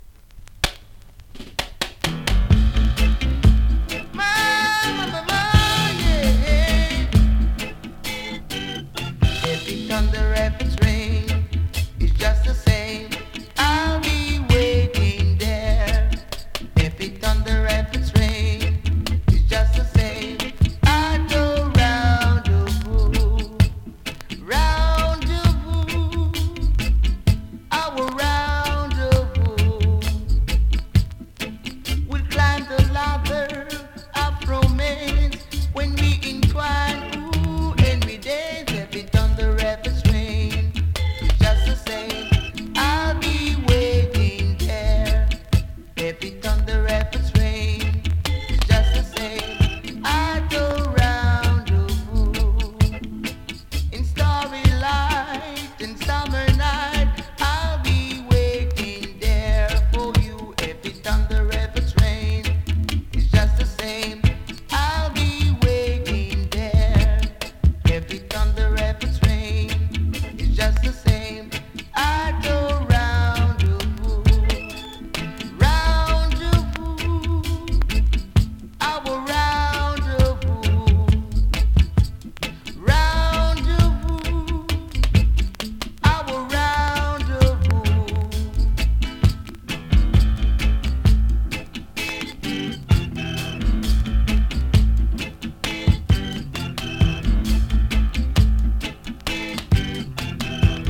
ホーム > 2017 NEW IN!!SKA〜REGGAE!!
コンディションVG++
スリキズ、ノイズ比較的少なめで